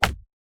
Arrow Impact 1.wav